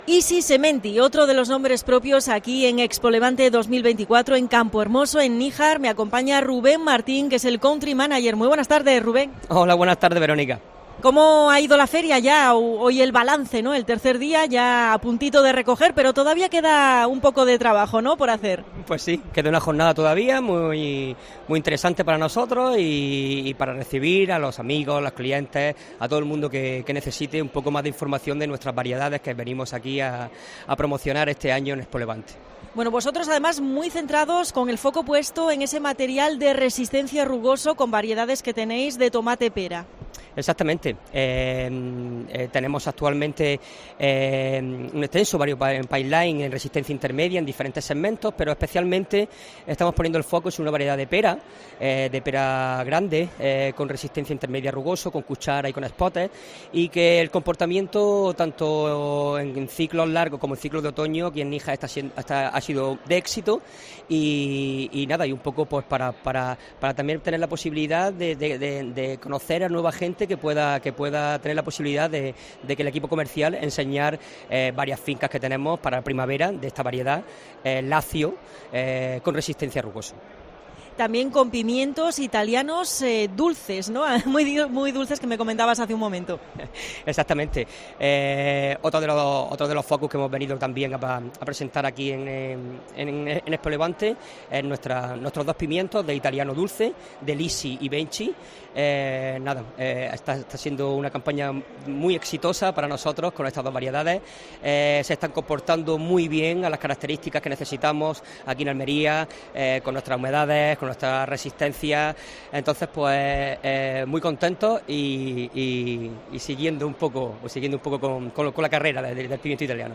Especial COPE Almería desde ExpoLevante en Níjar. Entrevista